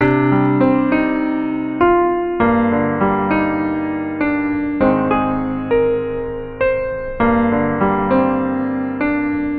明亮的钢琴
标签： 100 bpm Pop Loops Piano Loops 1.62 MB wav Key : Unknown
声道立体声